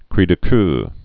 (krē də kœr)